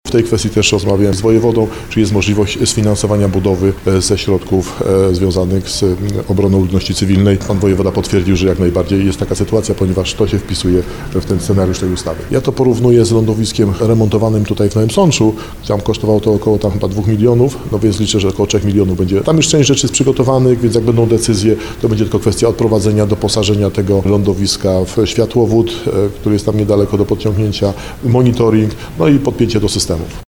– Projektowanie planowane jest do końca roku – mówi starosta Tadeusz Zaremba.